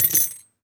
foley_keys_belt_metal_jingle_10.wav